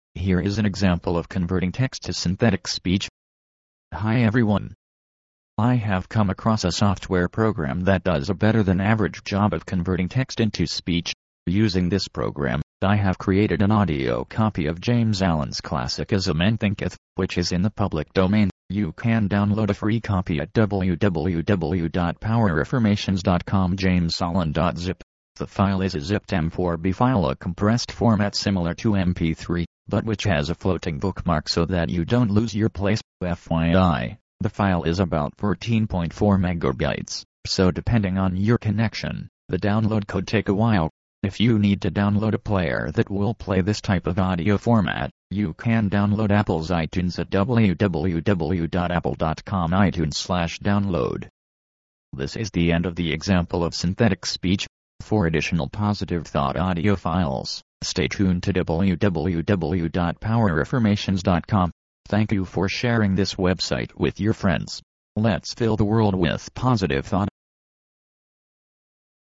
Using the licensed synthetic voice software, I have created an audio book of James Allen's classic "As a Man Thinketh."
The software and voice I used to convert the text into speech definitely sounds electronic. It's not perfect, but isn't as difficult to listen to and understand as you might think.